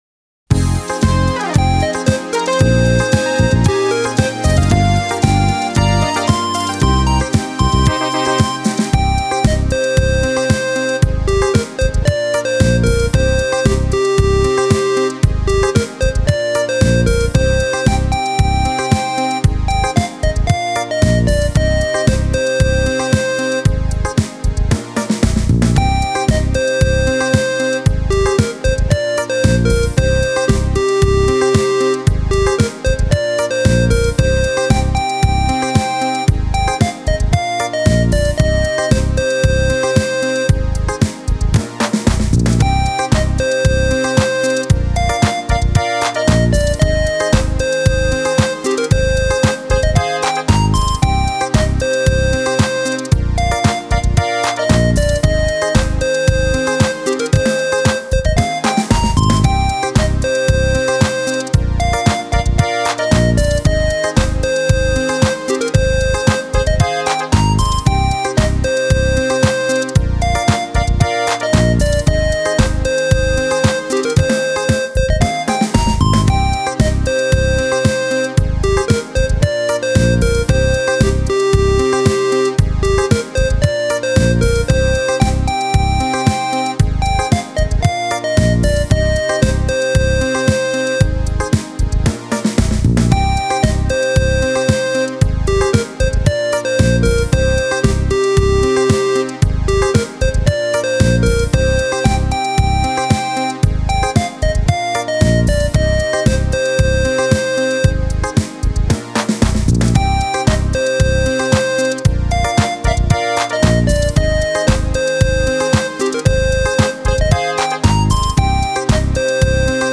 8 Beat
002 Disco Pop
Roland E X 10 8 Beat 002 Discopop Mp 3